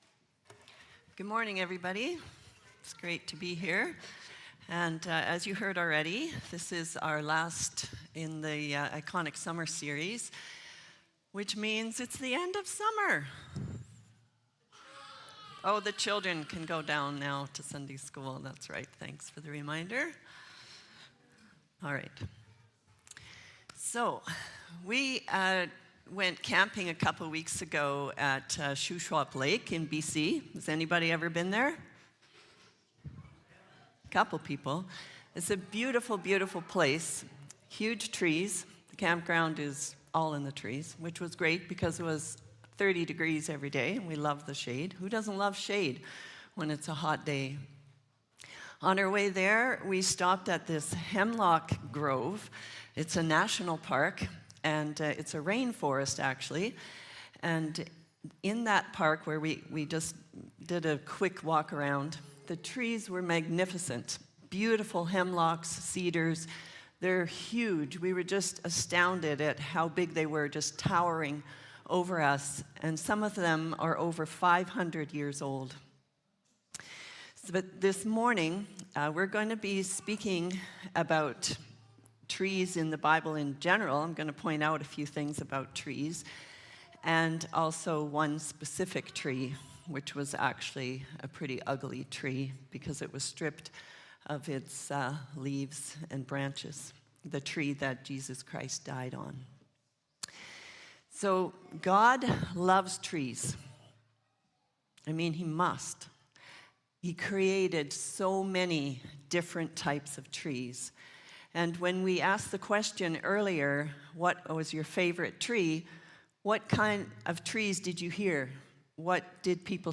Iconic Summer (2024) Current Sermon